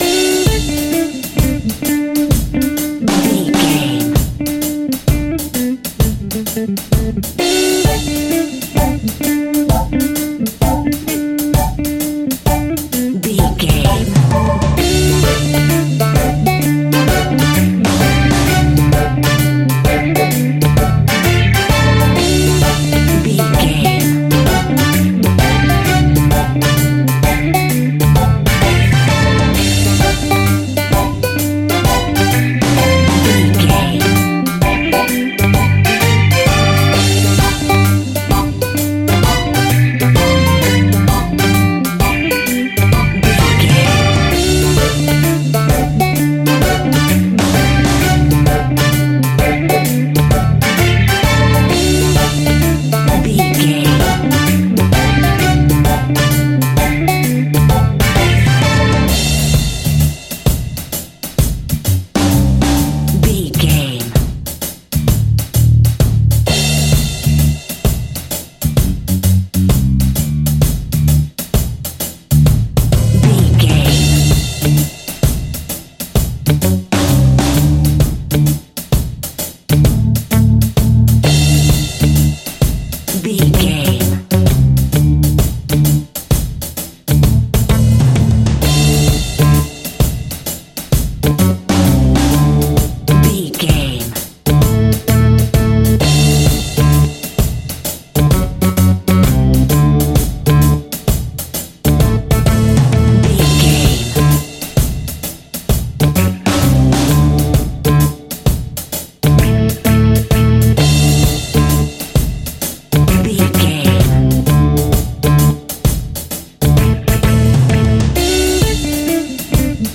Ionian/Major
dub
instrumentals
laid back
chilled
off beat
drums
skank guitar
hammond organ
transistor guitar
percussion
horns